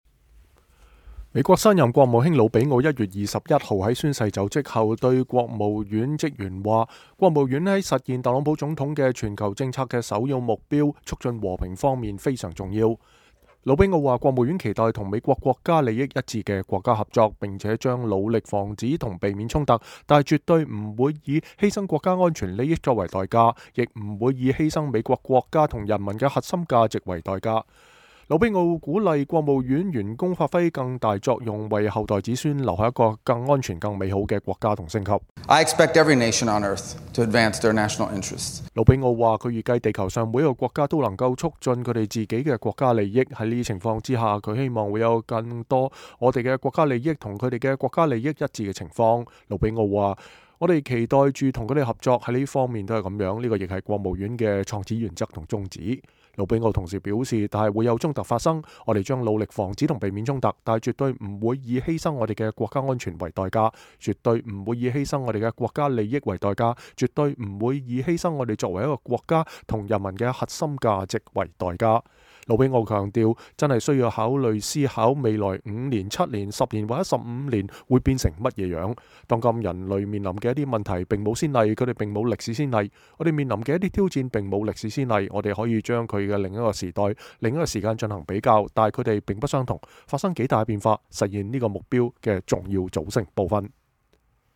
魯比奧首度對國務院發表演說：國務院在促進和平、避免衝突方面至為重要